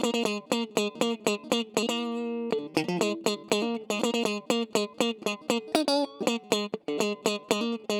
17 Pickin Guitar PT3.wav